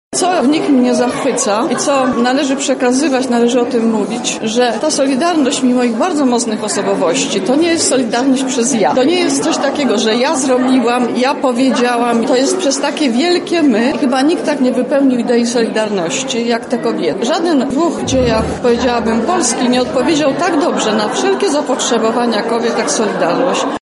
Trwa III Ogólnopolskie Forum Kobiet NSZZ „Solidarność”.